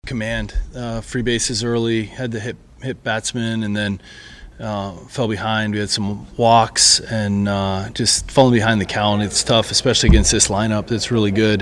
Manager Donnie Kelly says Mitch Keller just didn’t have it last night.